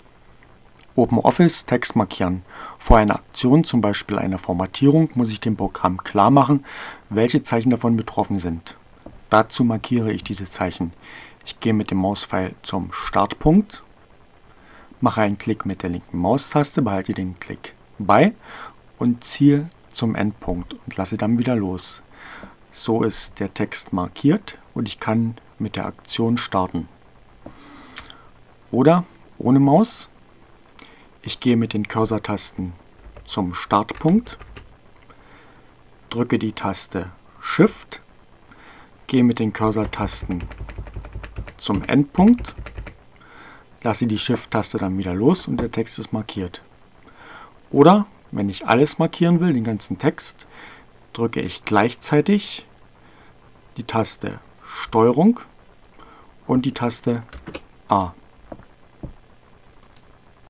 Tags: CC by-sa, Desktop, Fedora Core, Gnome, Linux, Neueinsteiger, Ogg Theora, ohne Musik, OpenOffice, screencast, short, Textverarbeitung